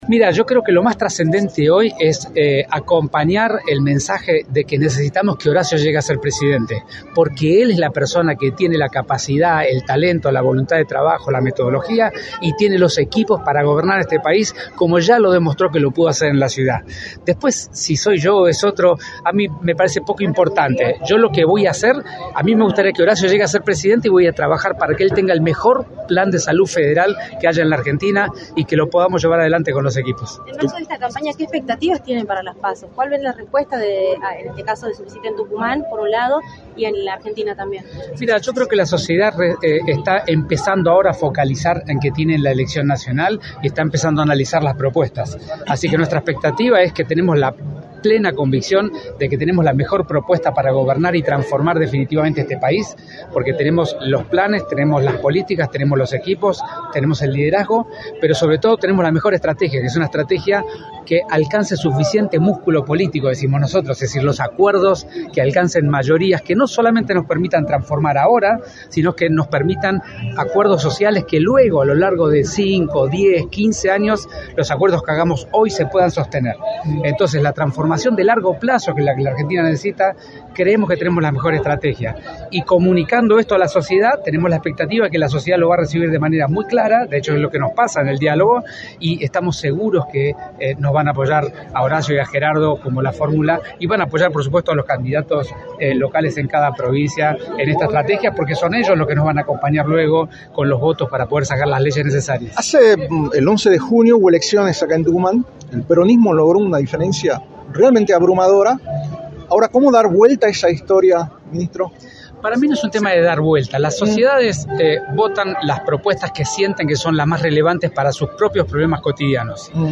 “Hay que acompañar el mensaje de que necesitamos que Horacio sea Presidente, él tiene la capacidad, el talento y la voluntad de trabajo y los equipos para gobernar este país, voy a trabajar para tener el mejor plan federal de salud” remarcó Quirós en «La Mañana del Plata» por la 93.9. “Estamos seguros que nos van a apoyar” añadió.